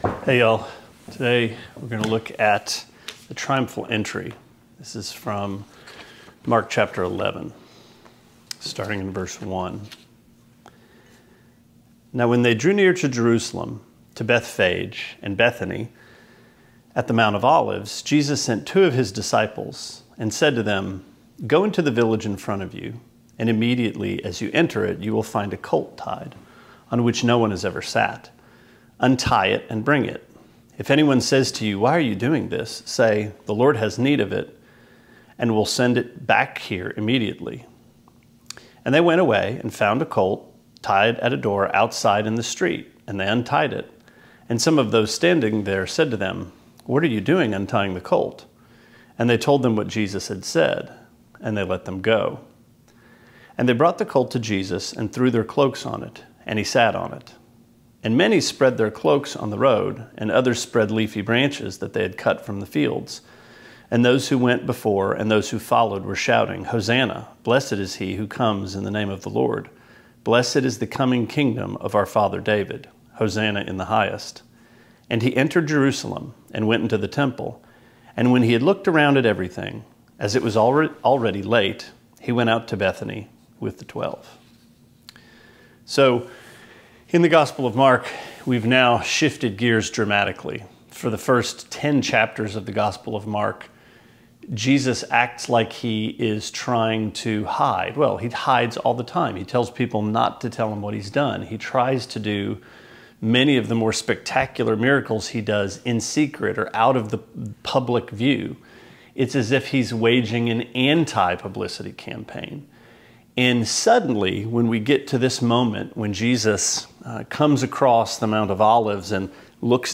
Sermonette 7/27: Mark 11:1-11: House Inspection